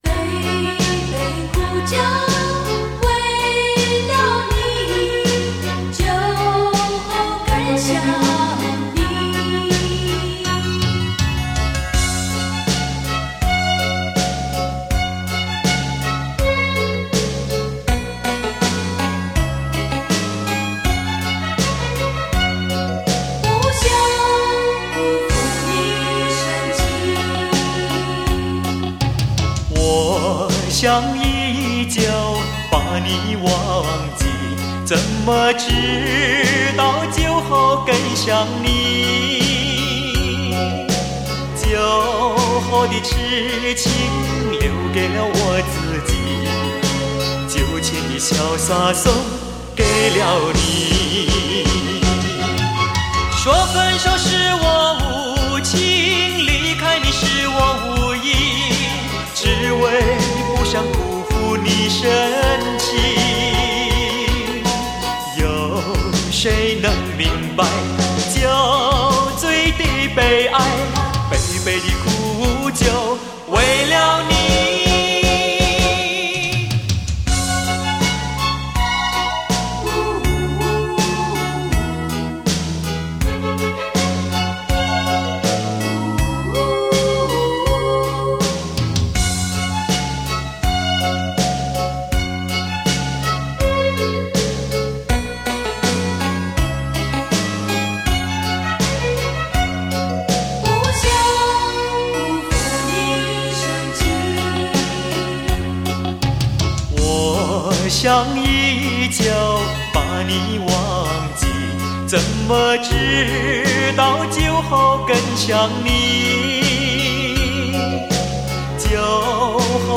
伤感 忧伤 优美的旋律